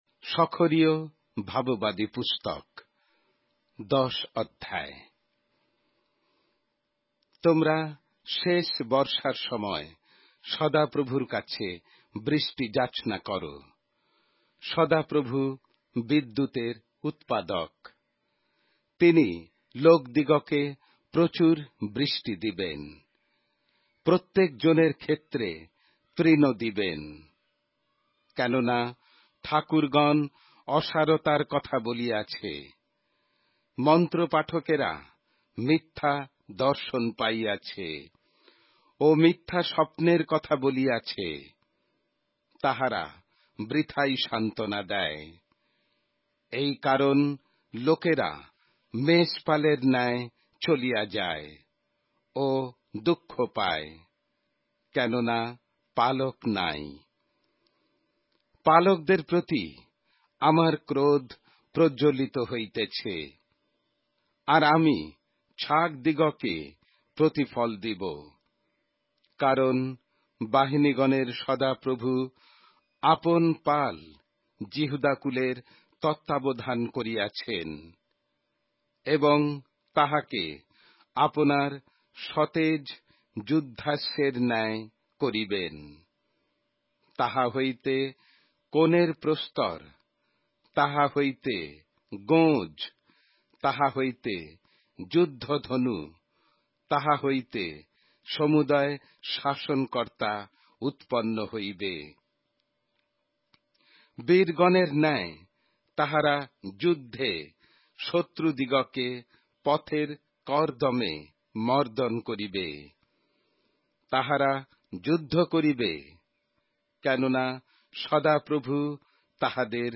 Bengali Audio Bible - Zechariah 4 in Rcta bible version